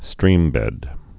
(strēmbĕd)